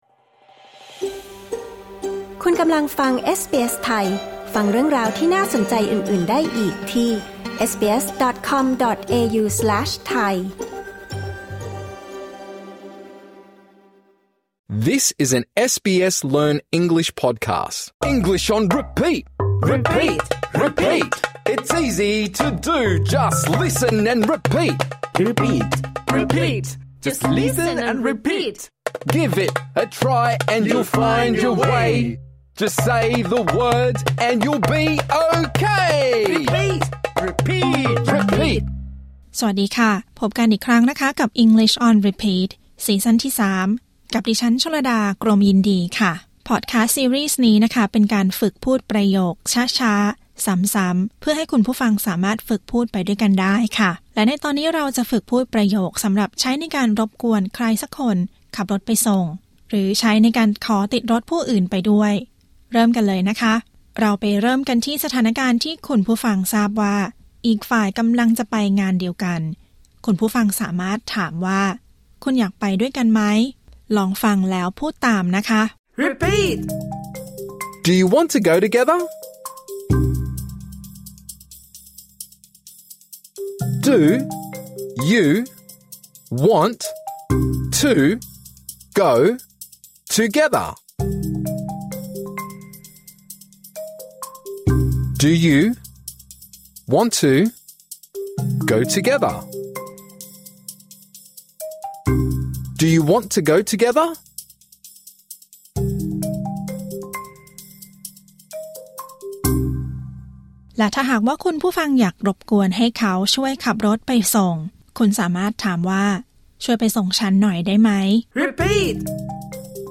| ขอบคุณที่ไปส่งนะ บทเรียนนี้สำหรับผู้ฝึกในระดับง่าย ในตอนนี้ เราจะฝึกพูดประโยคต่อไปนี้: คุณอยากไปด้วยกันไหม?